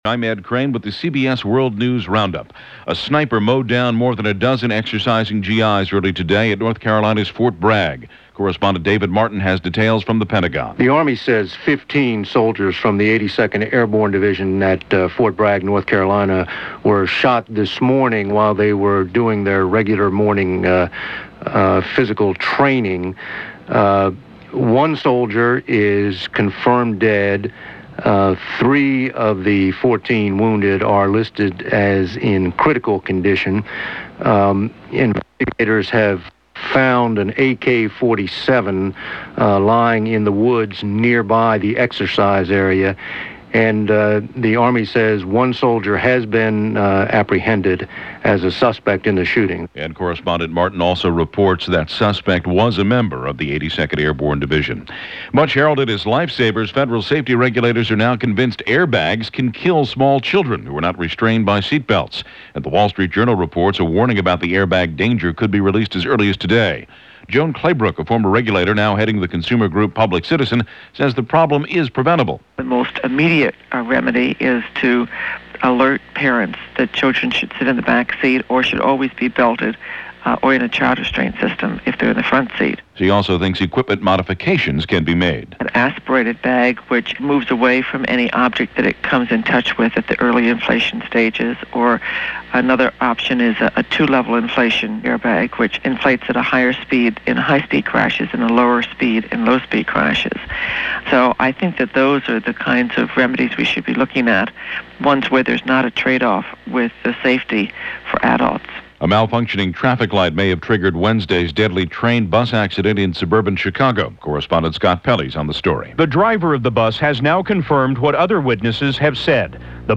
All that, and a lot more for this October 27, 1995 as reported by The CBS World News Roundup.